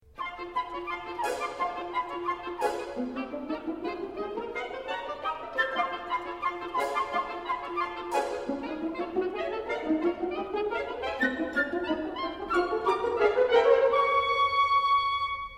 Dans le Scherzo, en haute tessiture, l'effectif orchestral est très allégé. Ce sont le bois augmentés de la petite flûte piccolo qui feront frétiller les poussins tandis que se tairont les cuivres et les contrebasses.